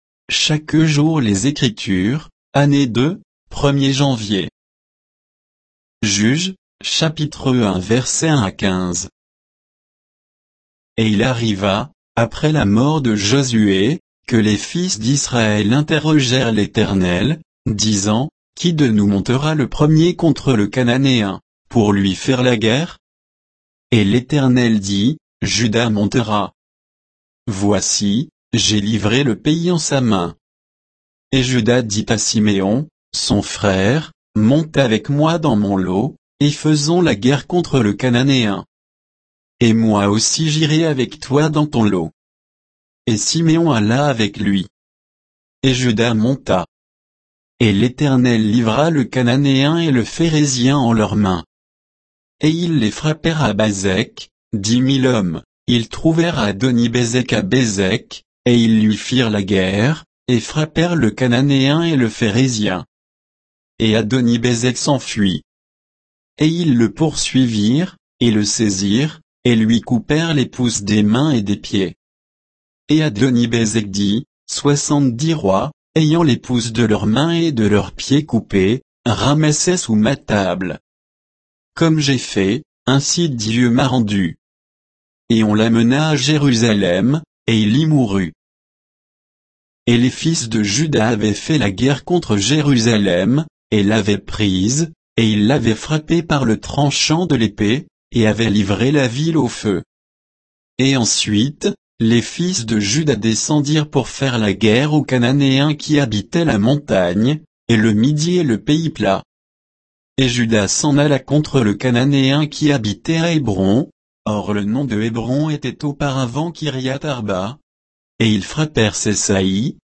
Méditation quoditienne de Chaque jour les Écritures sur Juges 1, 1 à 15